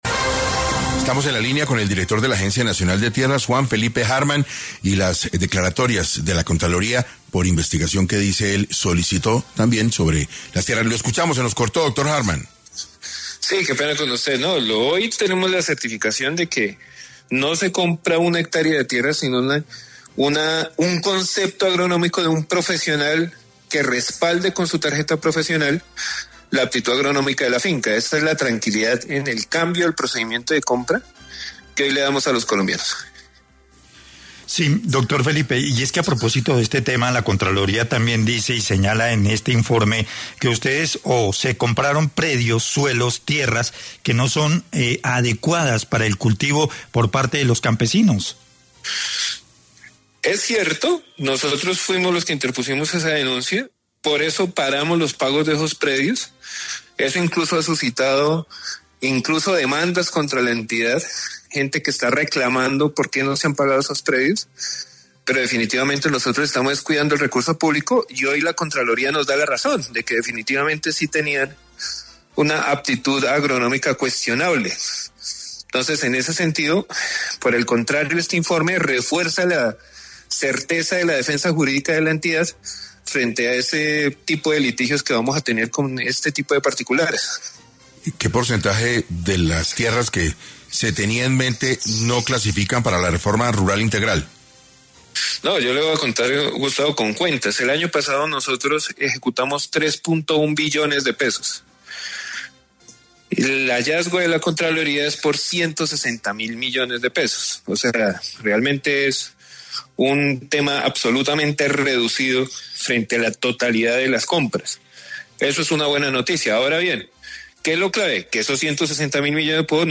Felipe Harman, director de la Agencia Nacional de Tierras, dijo en 6AM que la gente que está bloqueando el Boquerón está perdiendo el tiempo porque no aparecen como reclamantes de tierras.